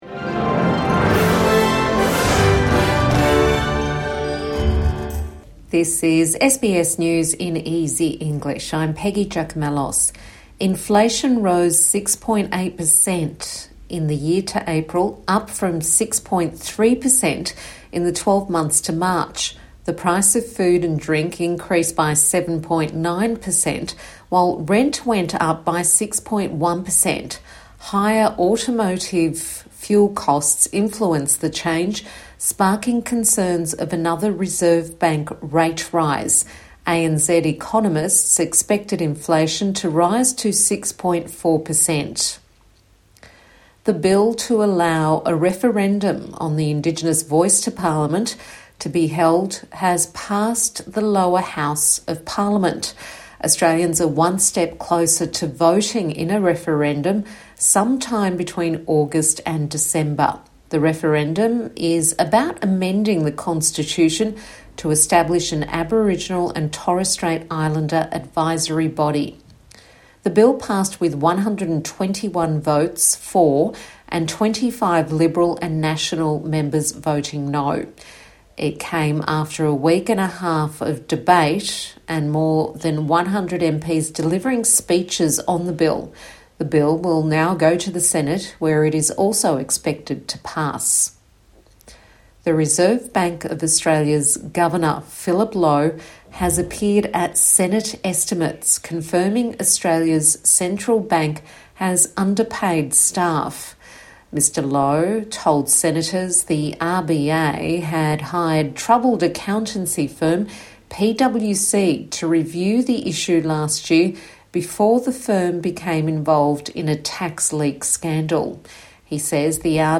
A five-minute news bulletin for English language learners.